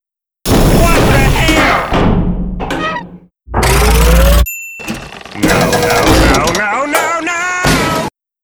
mvm_tank_deploy.wav